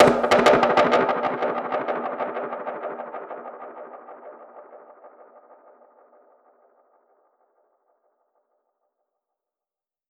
DPFX_PercHit_C_95-10.wav